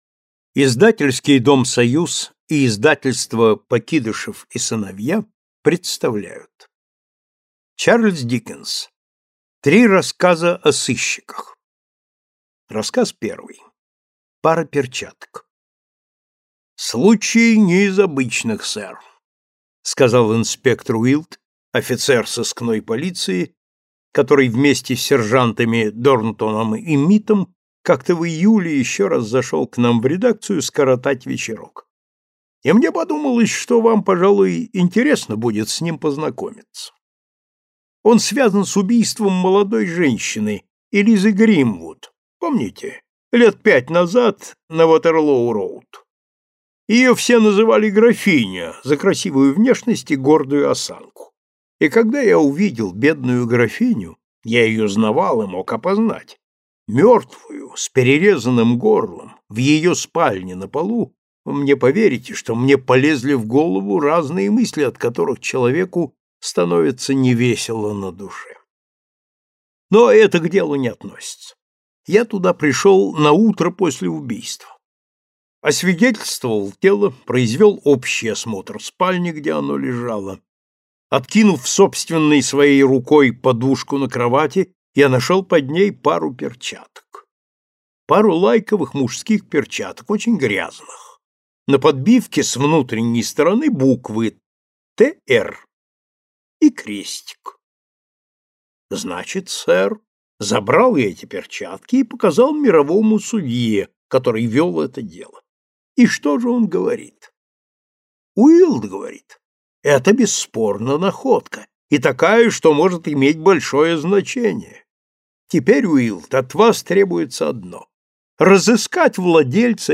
Aудиокнига Классика зарубежного рассказа № 25 Автор Пелам Гренвилл Вудхаус Читает аудиокнигу Александр Клюквин.